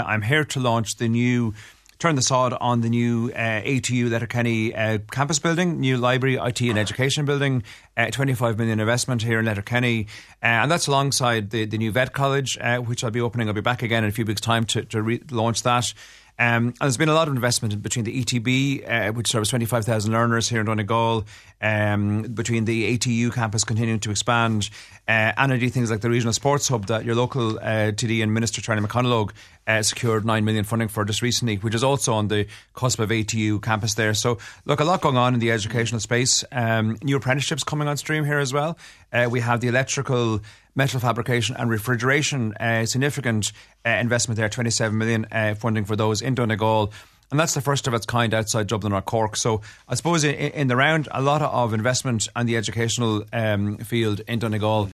He told today’s Nine ’til Noon Show that there is a lot of work ongoing to increase the provision of education in Donegal: